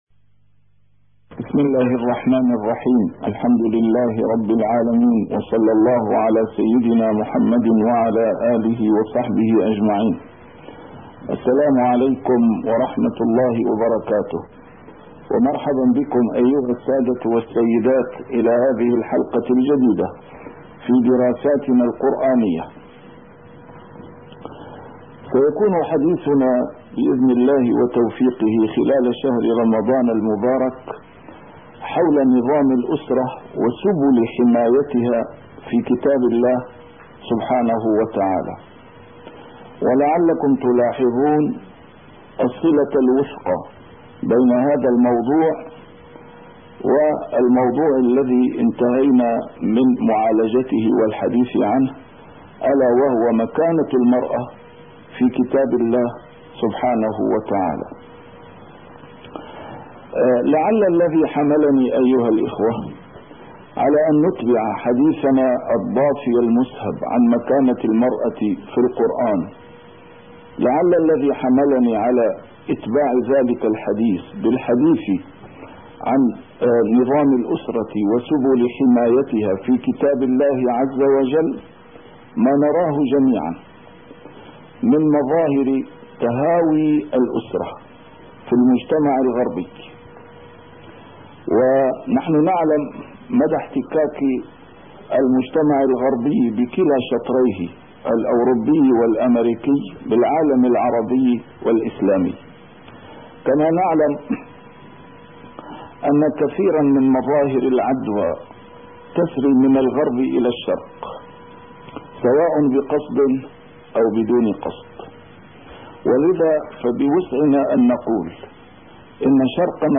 A MARTYR SCHOLAR: IMAM MUHAMMAD SAEED RAMADAN AL-BOUTI - الدروس العلمية - دراسات قرآنية - نظام الأسرة وسبل حمايتها في كتاب الله عز وجل